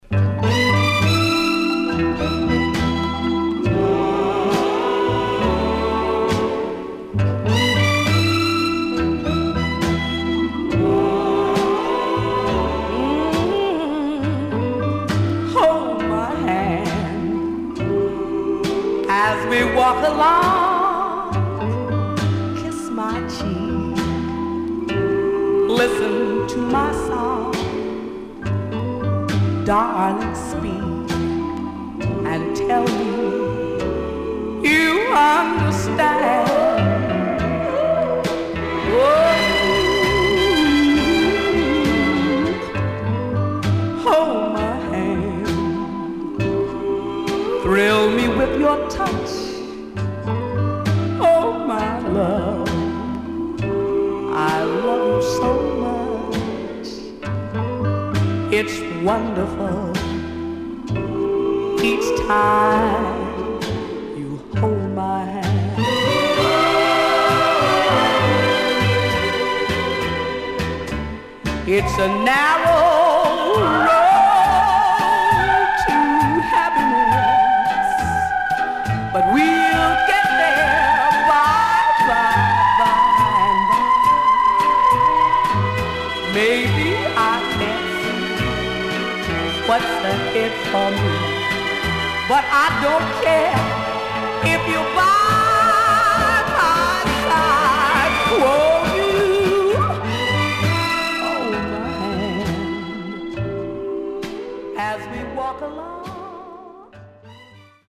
SIDE A:盤質は良好です。盤面はきれいです。